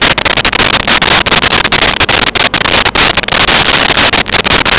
Sfx Amb Hangar Loop
sfx_amb_hangar_loop.wav